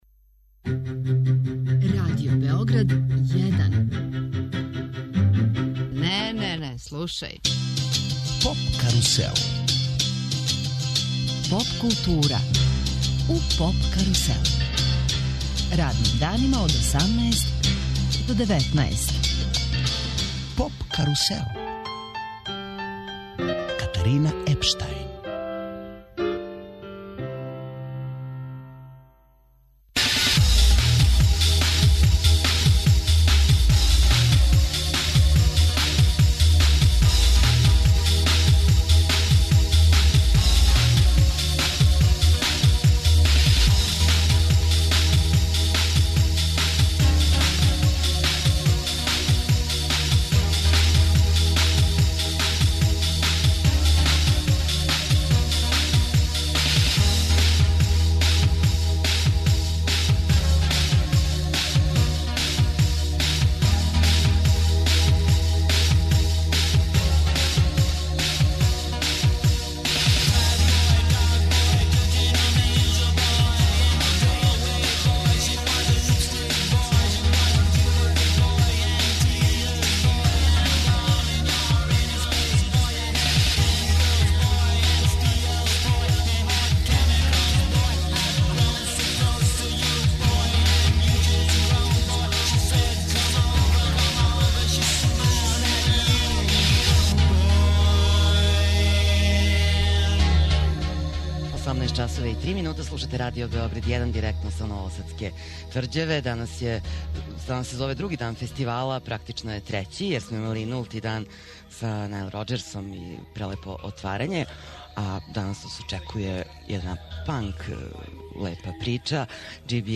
Сваког дана резимирамо претходну ноћ, слушамо извођаче и преносимо део атмосфере.